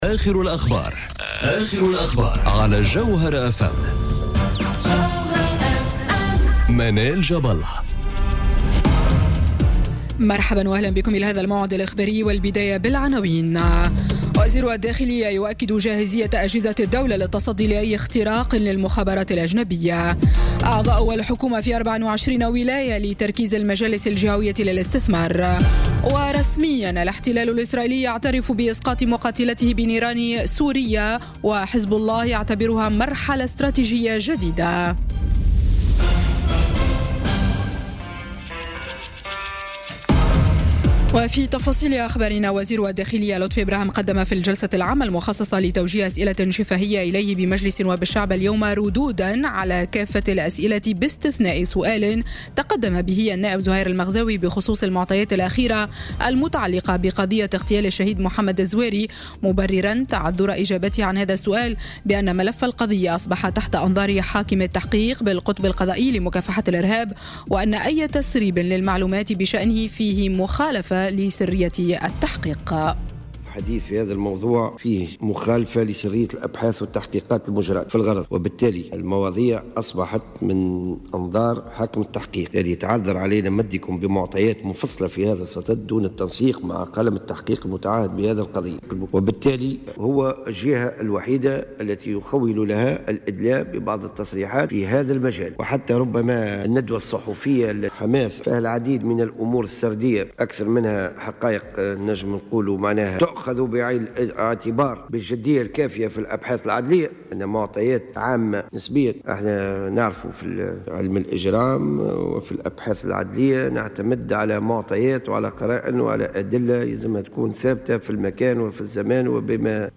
نشرة أخبار السابعة مساء ليوم السبت 10 فيفري 2018